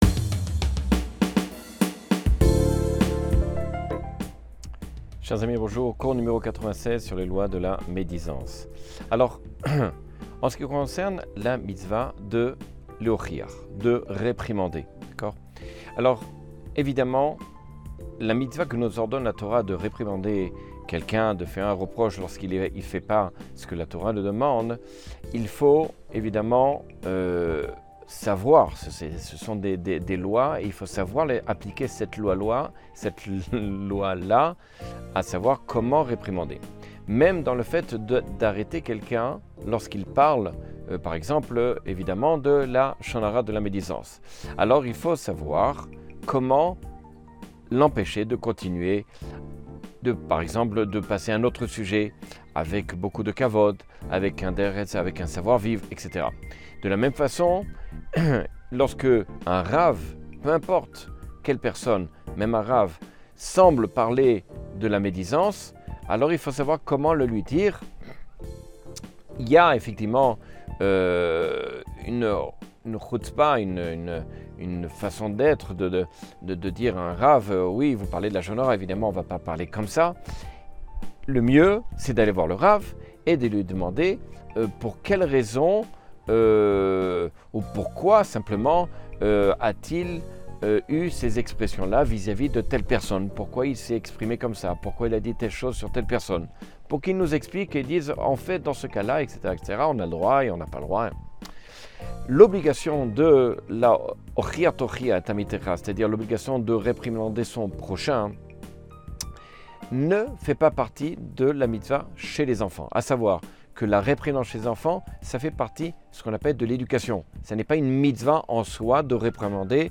Cours 96 sur les lois du lashon hara.